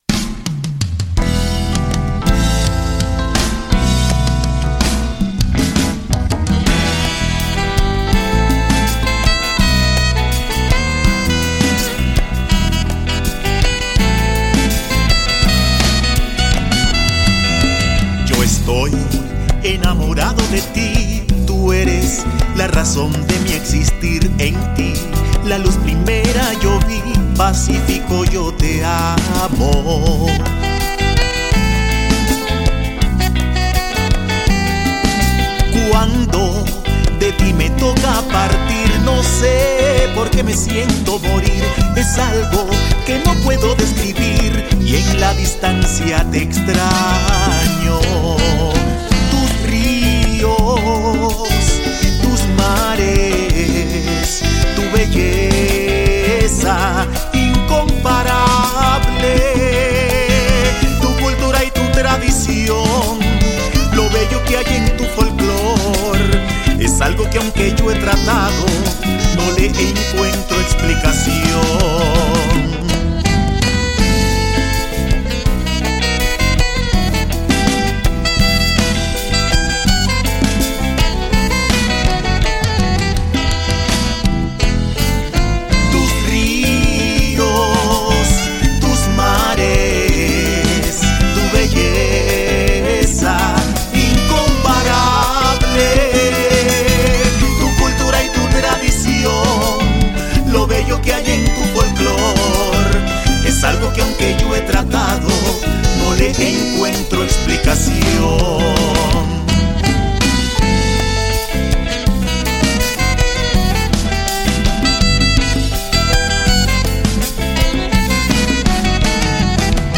Canción
Congas.
Guitarra.
Batería.
Teclado.
Coro.